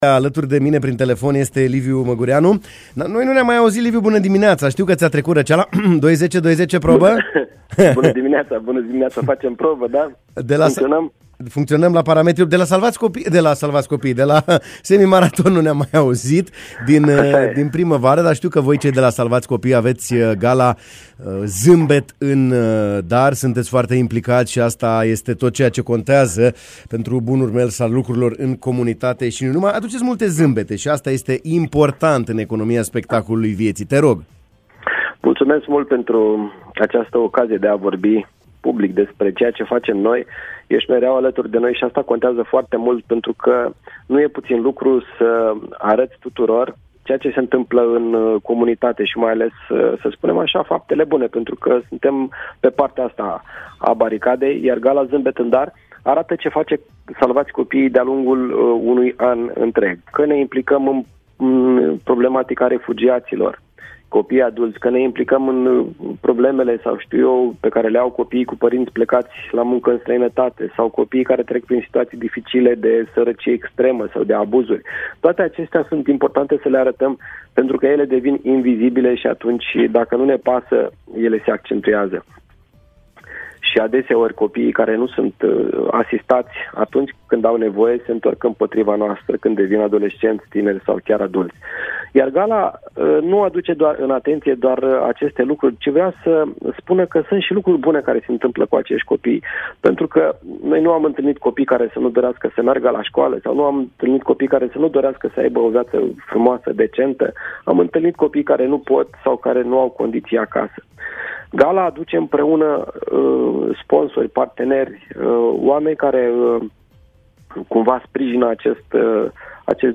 în direct la Radio Iași